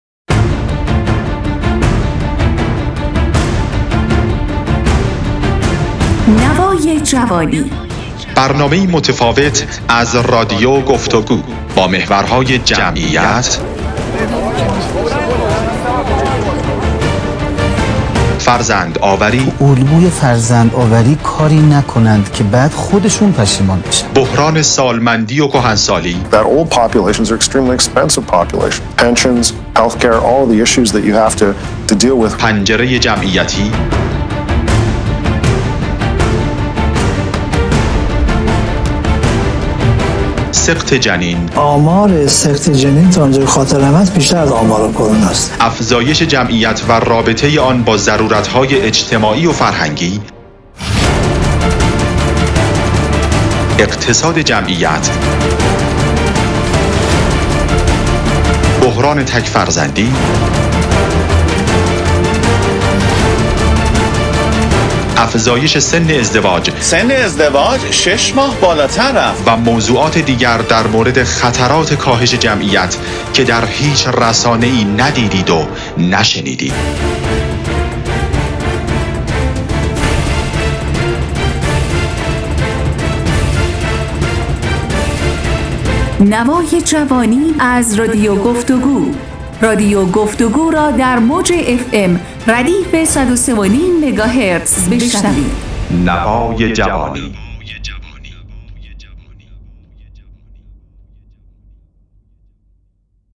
پخش "نوای جوانی" با موضوعات جمعیتی از رادیو گفتگو تیزر صوتی - تسنیم
برنامه "نوای جوانی" برنامه‌ای گفتگو‌محور با موضوع جمعیت است و به‌زودی از رادیو گفتگو پخش خواهد شد.